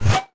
bullet_flyby_fast_12.wav